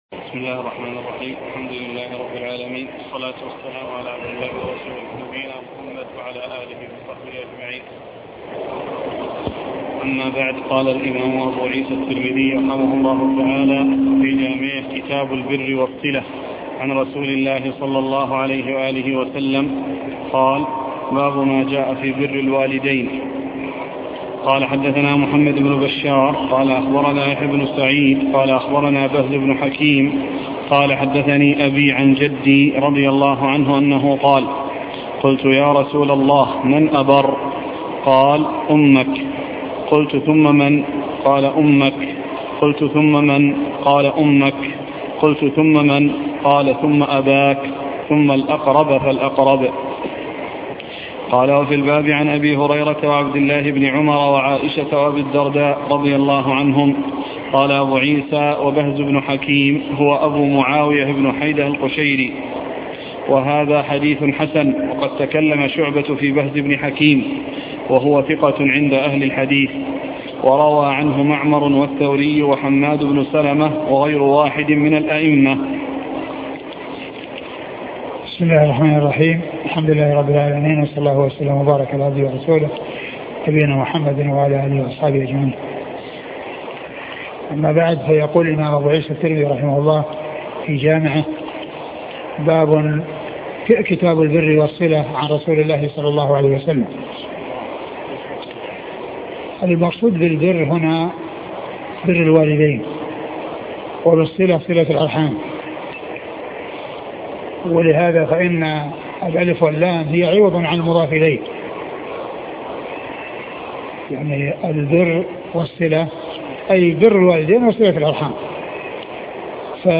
شرح سنن الترمذي الدرس عدد 209 كتاب البر والصلة